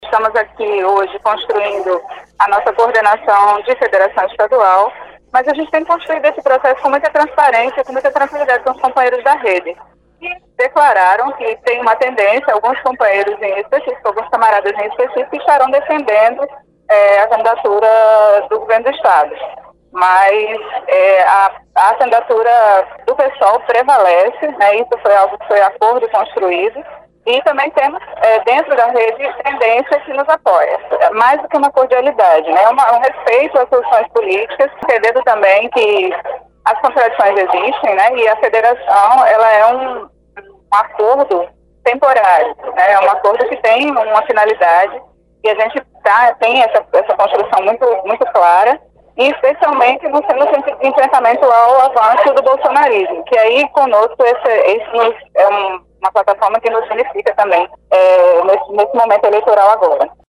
foi registrado pelo programa Correio Debate, da 98 FM, de João Pessoa, nesta sexta-feira (27/05).